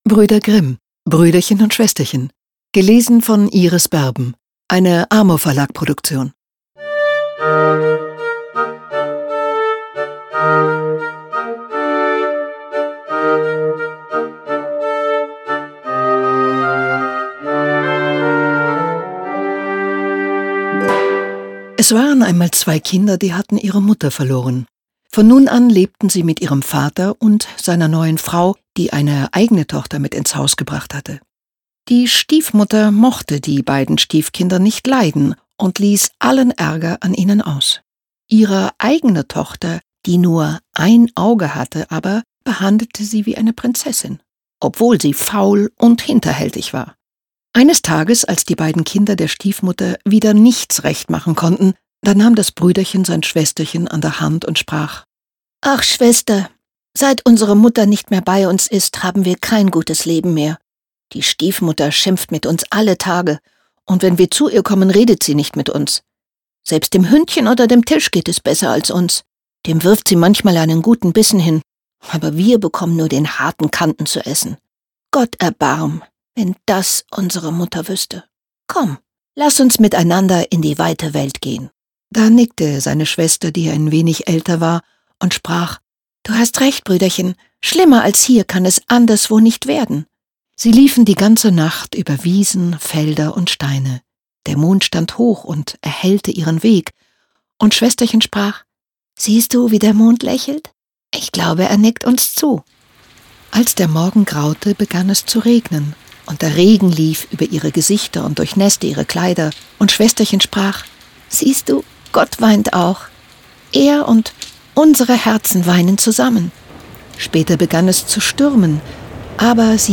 CD mit Musik und Geräuschen
Iris Berben (Sprecher)